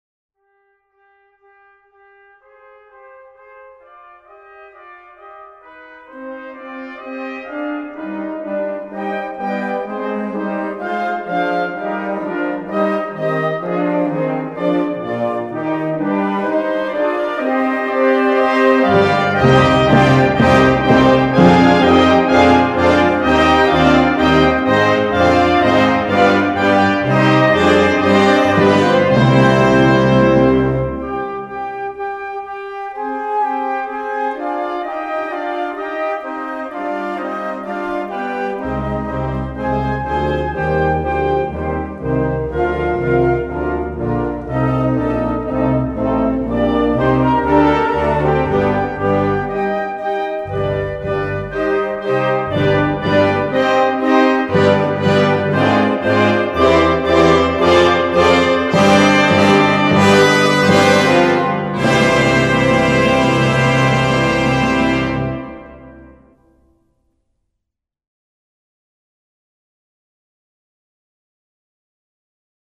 Gattung: Kirchenmusik
Besetzung: Blasorchester